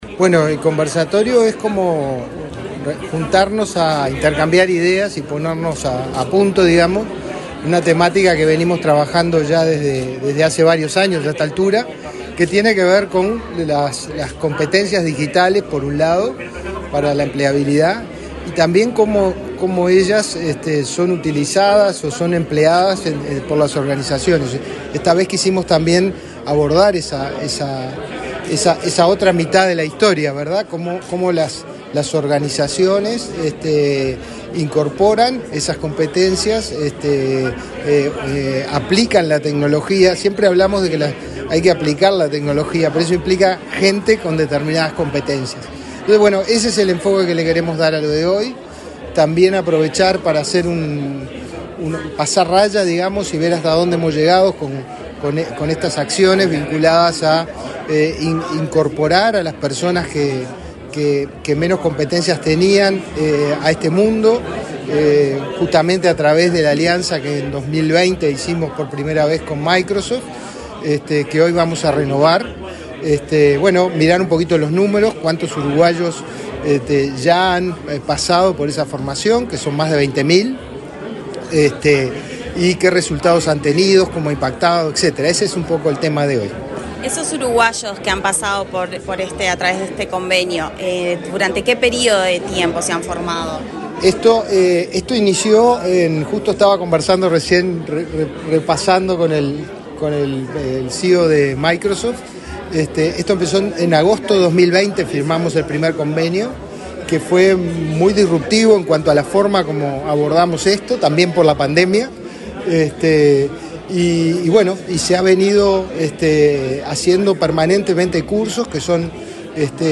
Entrevista al director de Inefop, Pablo Darscht
Entrevista al director de Inefop, Pablo Darscht 26/06/2024 Compartir Facebook X Copiar enlace WhatsApp LinkedIn El Instituto Nacional de Empleo y Formación Profesional (Inefop) organizó, este miércoles 26 en Montevideo, un conversatorio dedicado a las competencias digitales y su relevancia para la empleabilidad. El director del organismo, Pablo Darscht, dialogó con Comunicación Presidencial acerca de la importancia de esta temática.